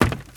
HauntedBloodlines/STEPS Wood, Creaky, Run 10.wav at main - HauntedBloodlines - Gitea: Git with a cup of tea
STEPS Wood, Creaky, Run 10.wav